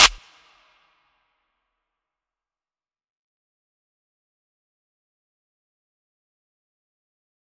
Claps
DMV3_Clap 3.wav